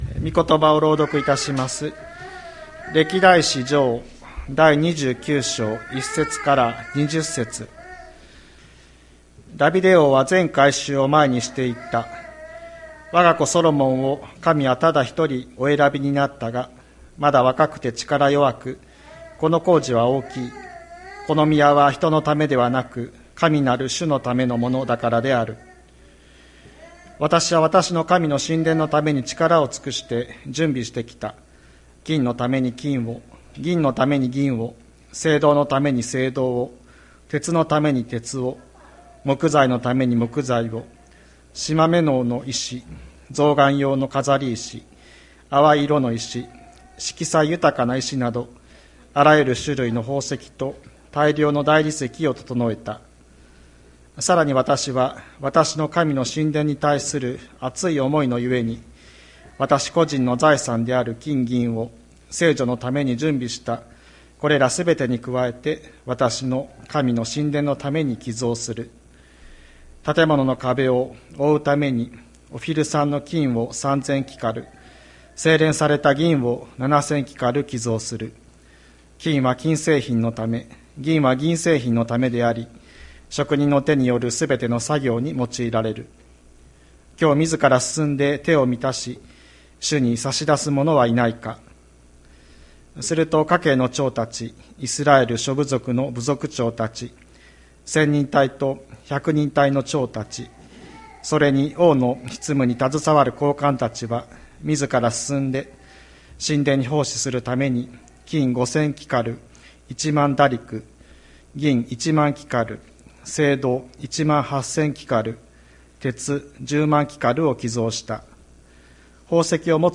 千里山教会 2023年01月22日の礼拝メッセージ。